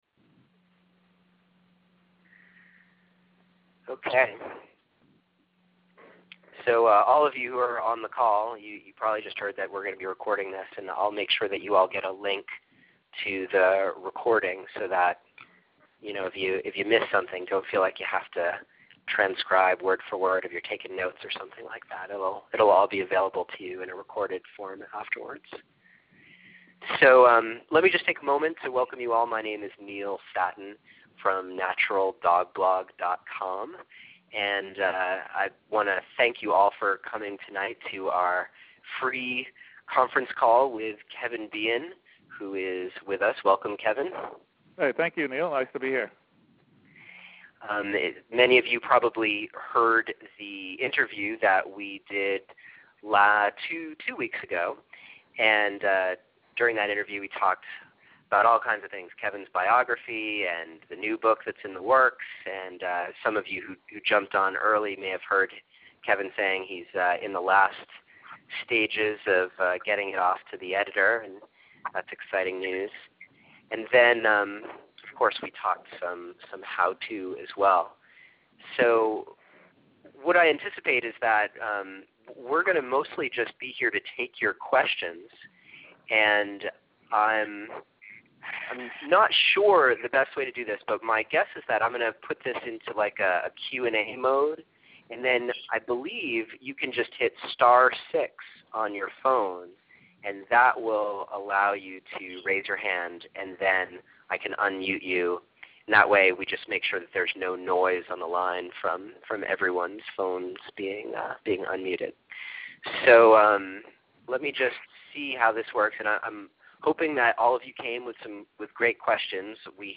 We had some great questions from callers all over the country, and I thought you would appreciate being able to hear the results.
And here is the conference call, in its entirety, for your listening pleasure.